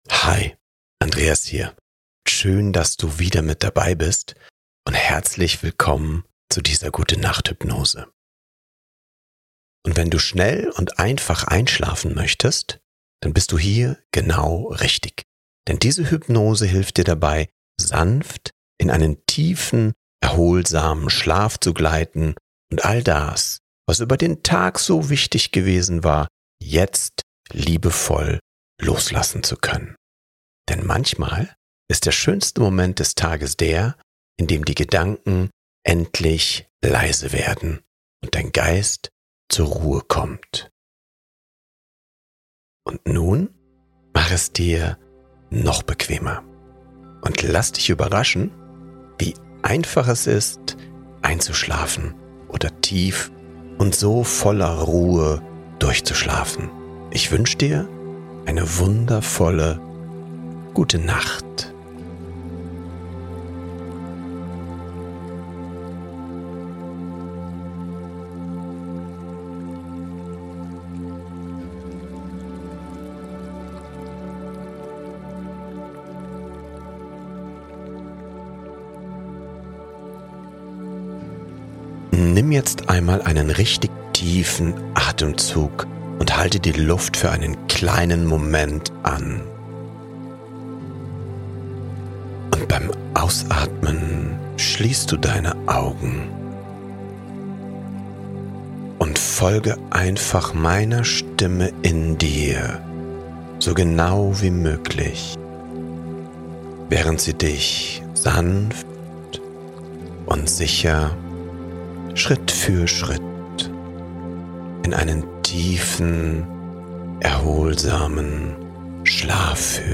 GUTE NACHT HYPNOSE: Gedanken loslassen & ruhig einschlafen ~ Happiness Mindset Podcast